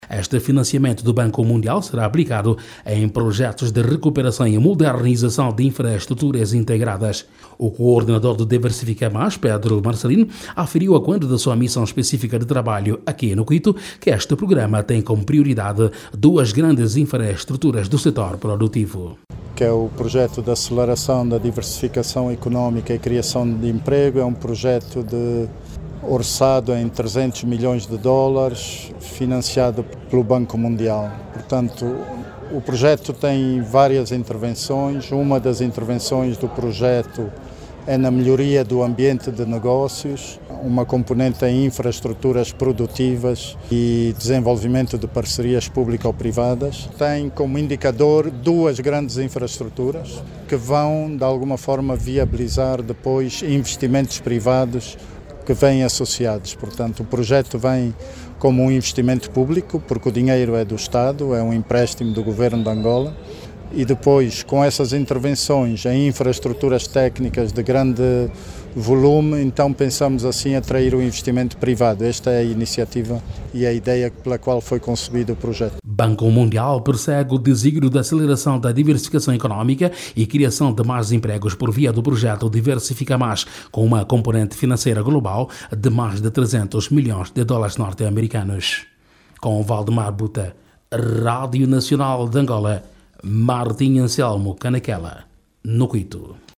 Mais de 200 milhões de dólares norte-americanos foram disponibilizados para o projecto Diversifica Mais, implementado nas províncias que compõem o Corredor do Lobito. O Financiamento do Banco Mundial, visa melhorar a qualidade dos serviços prestados nas referidas regiões. Clique no áudio abaixo e ouça a reportagem